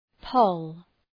poll Προφορά
{pəʋl}
poll.mp3